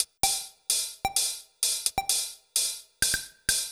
TEC Beat - Mix 12.wav